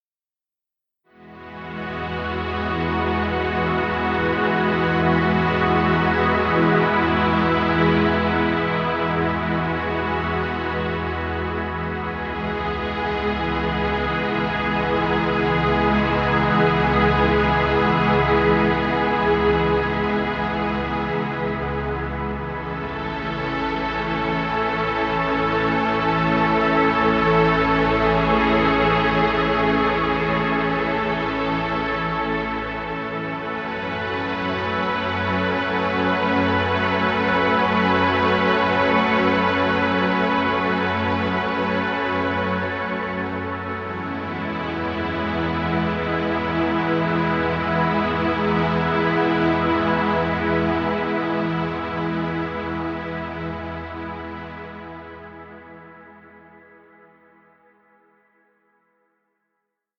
Relaxing music.